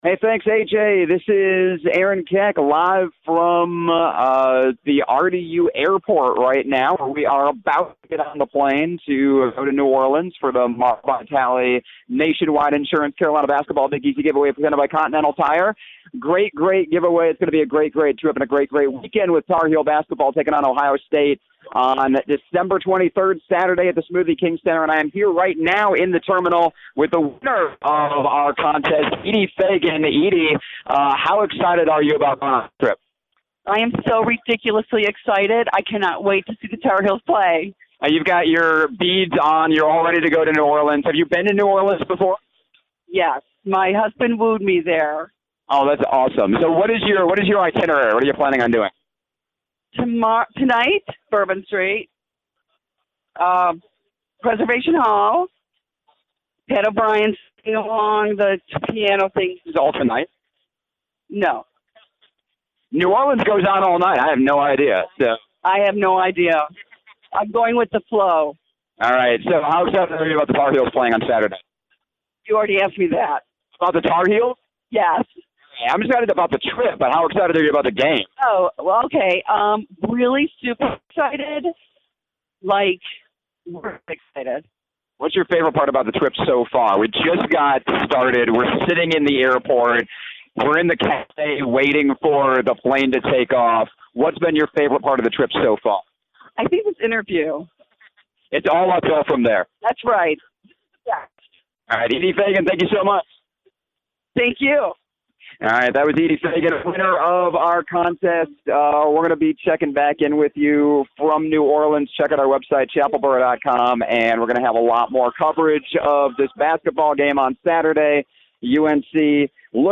Live from RDU Airport